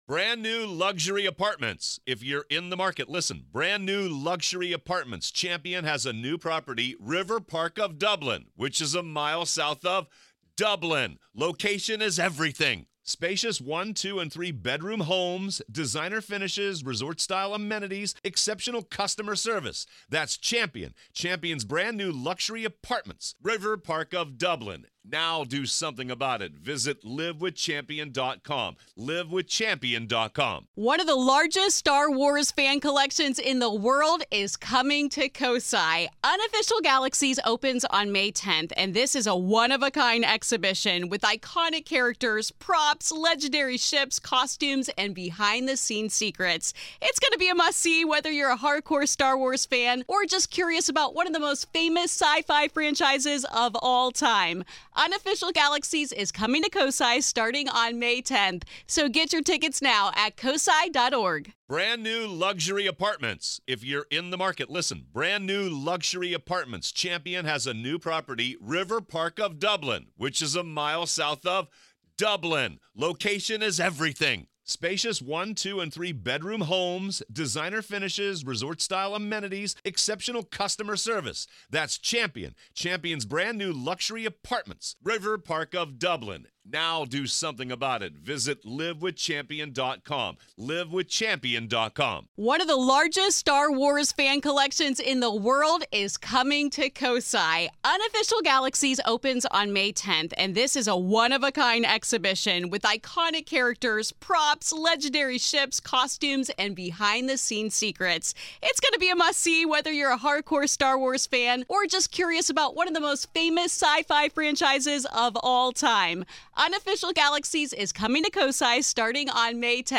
Only hours out of todays SCIF on UAP, Rep. Tim Burchett joined me for a few minutes to discuss what he could & next steps